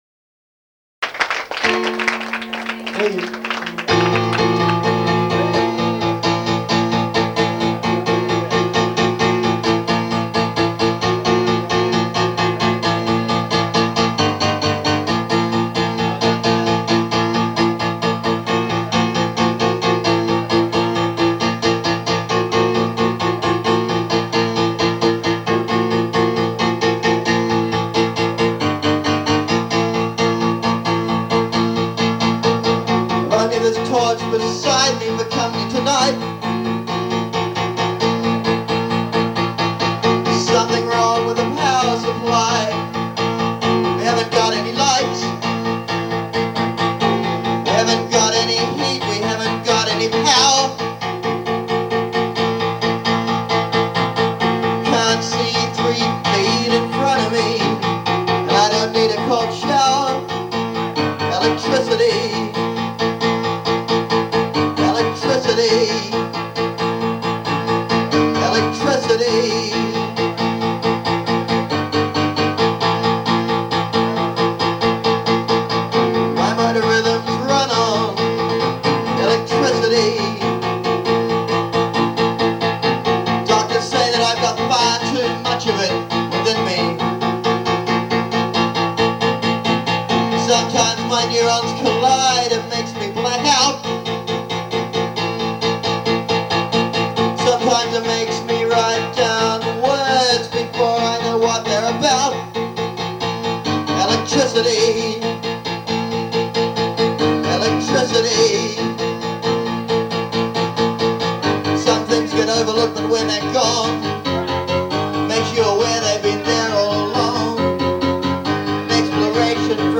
This was recorded in the audience at the Khyber on 8/19/93.